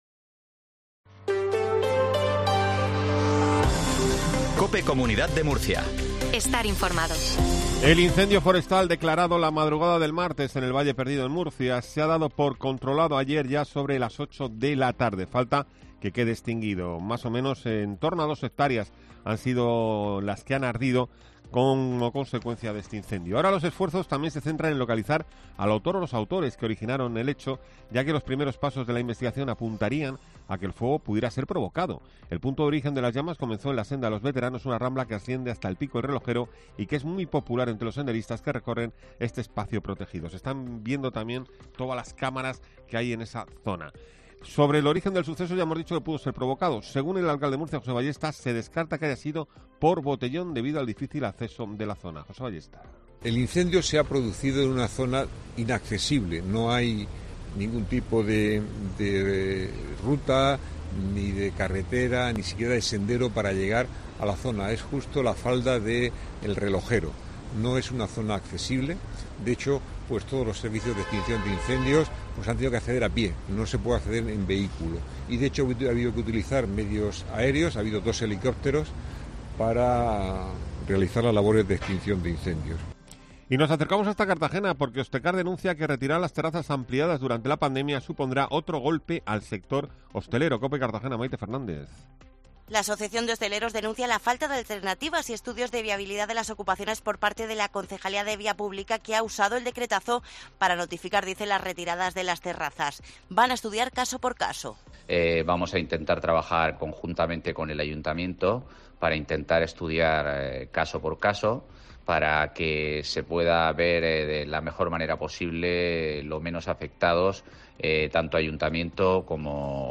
INFORMATIVO MATINAL REGION DE MURCIA 0720